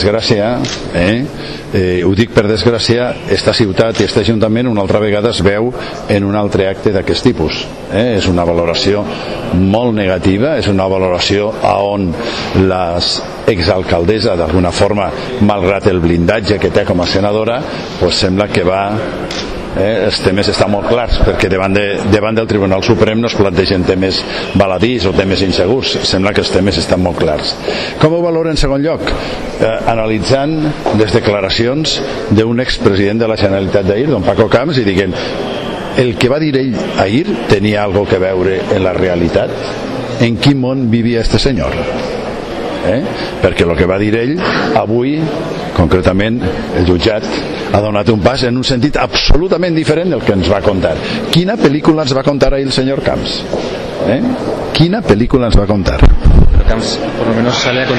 En esta valoración ante los medios Ribó se ha referido a las declaraciones realizadas ayer por el ex presidente de la Generalitat Francisco Camps.